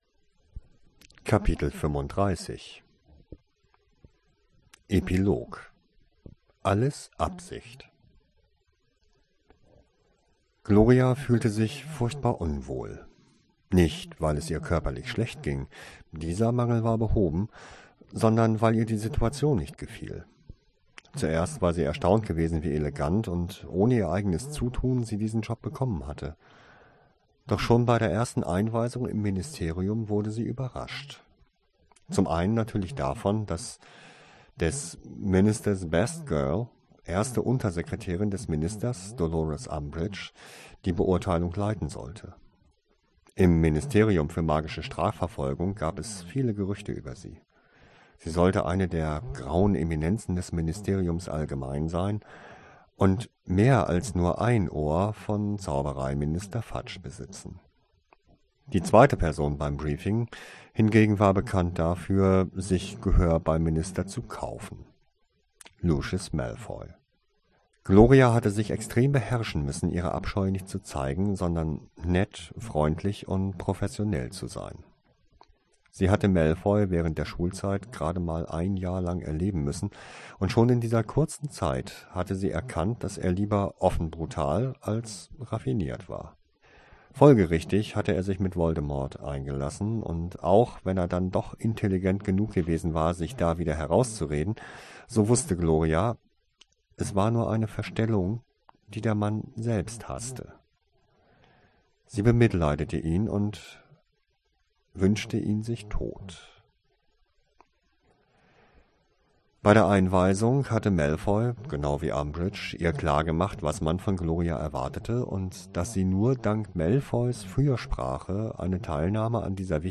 Geheimnisse der Vergangenheit [Original Hörbuch] - Abgeschlossen Podcast - Kapitel 35 | Epilog (Alles Absicht!)